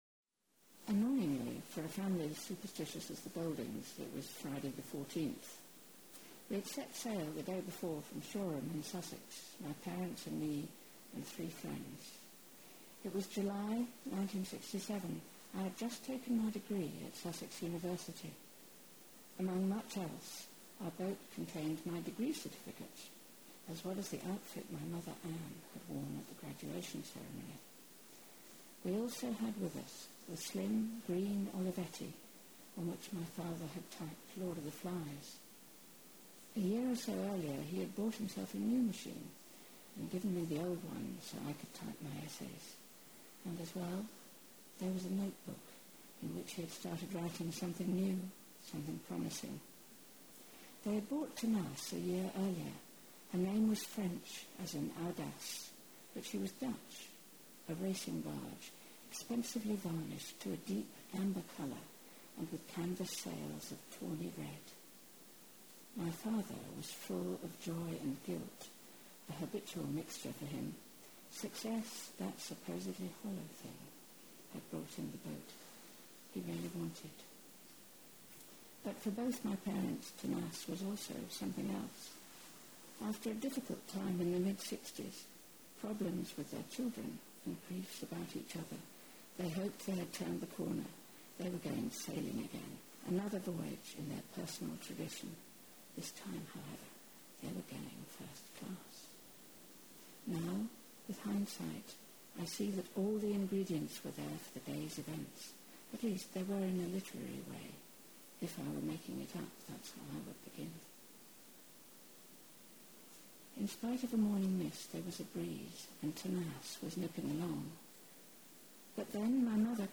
reading extracts from the book
Reading 1 “Annoyingly for a family as superstitious as the Goldings, it was Friday the fourteenth.